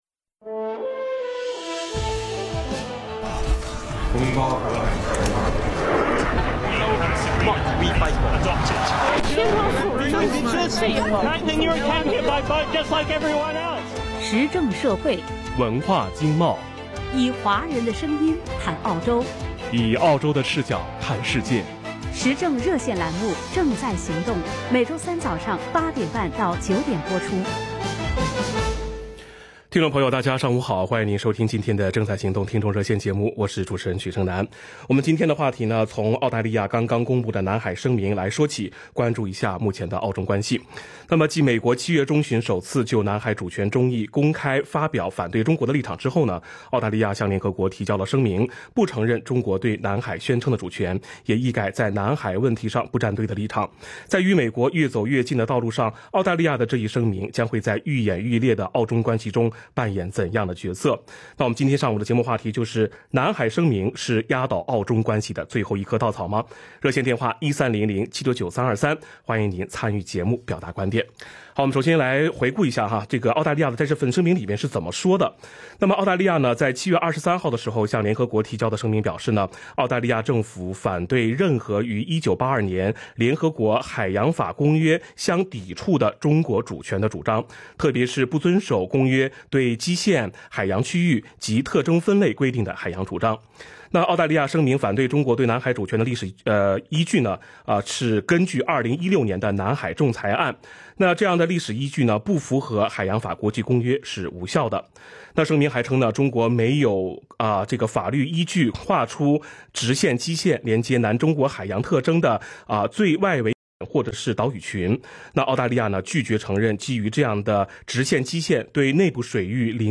action_talkback_july_29_new.mp3